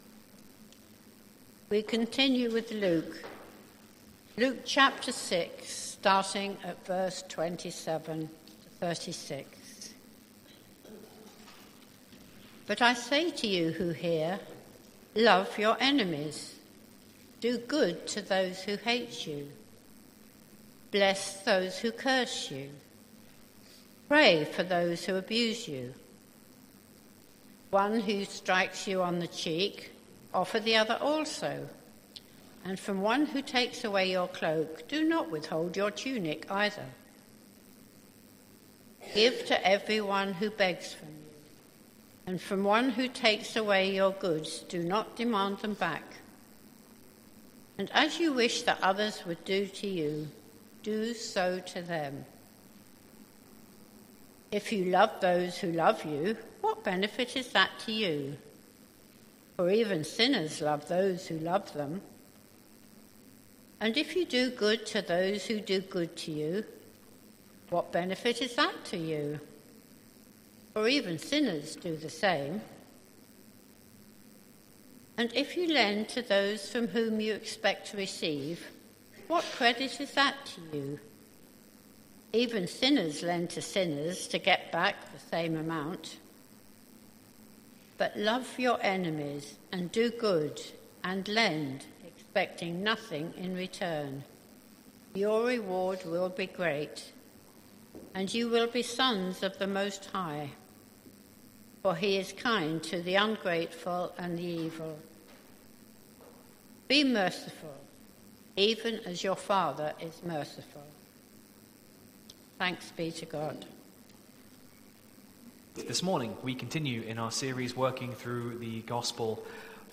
Sermon Series: Luke’s Gospel